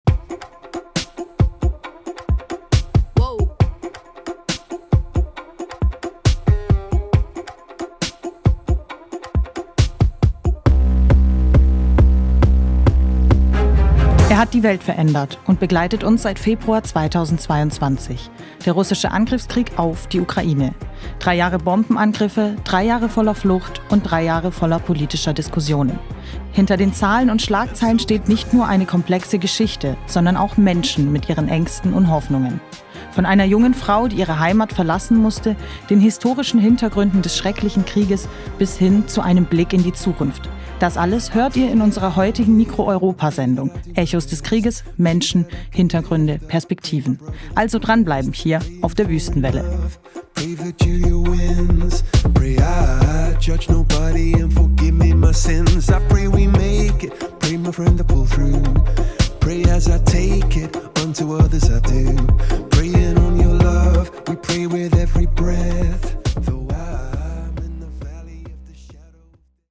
Feature: „Echos des Krieges" (561)